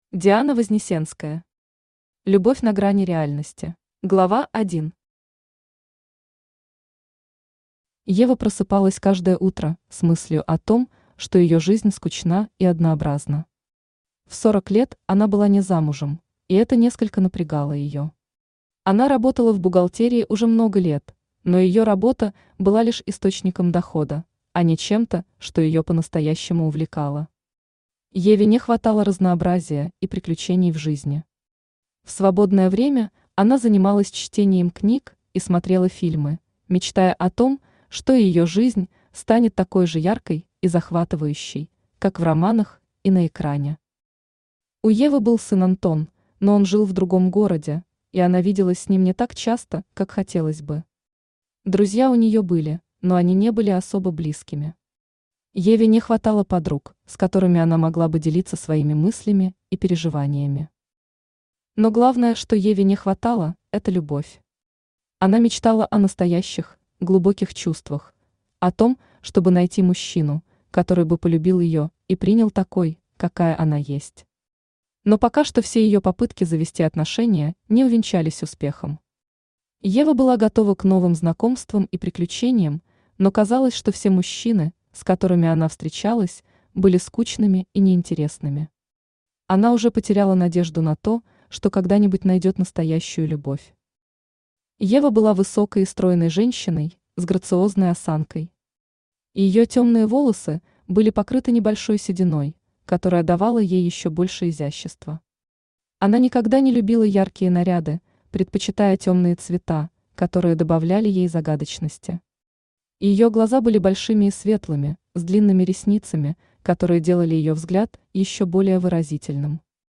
Аудиокнига Любовь на грани реальности | Библиотека аудиокниг
Aудиокнига Любовь на грани реальности Автор Диана Вознесенская Читает аудиокнигу Авточтец ЛитРес.